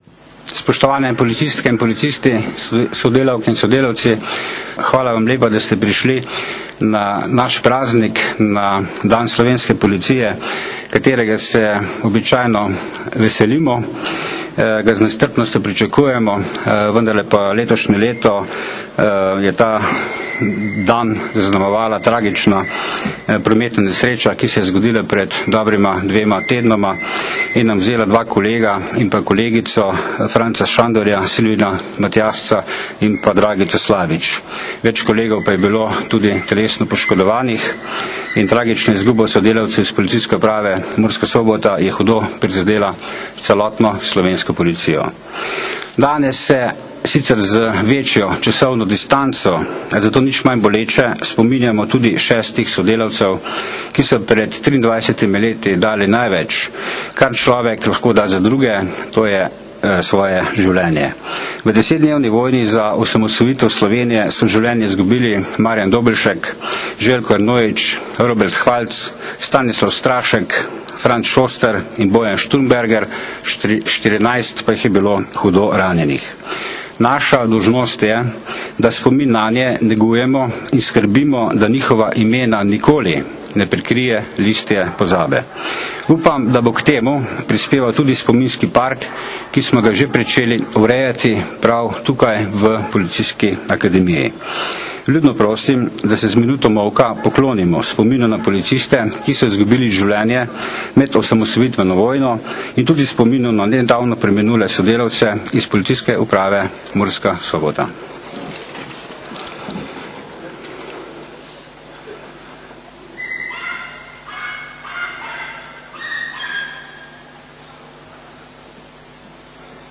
Letošnja osrednja slovesnost, s katero smo počastili osamosvojitveno vlogo slovenskih miličnikov, je bila danes, 27. junija 2014, v Policijski akademiji.
slika generalnega direktorja policije med govorom na slovesnosti ob dnevu policije
Zvočni posnetek govora generalnega direktorja policije (mp3)